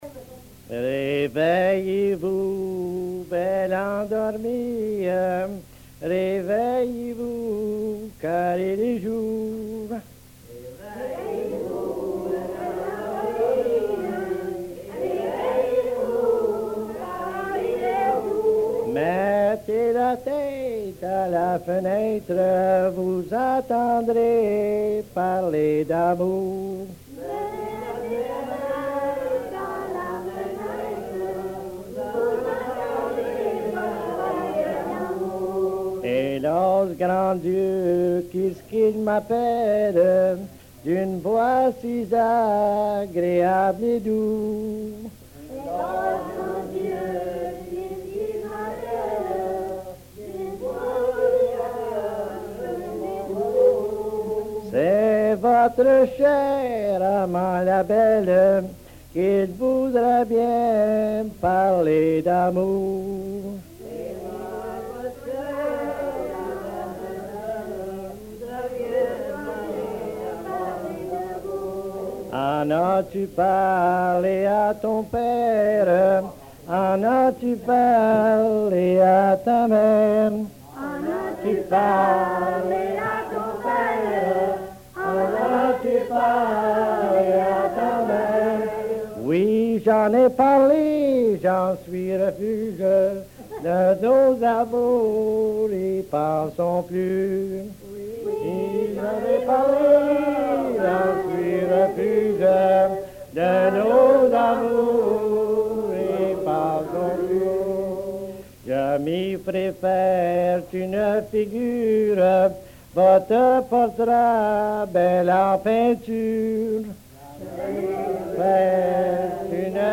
Folk Songs, French--New England
Franco-Americans--Music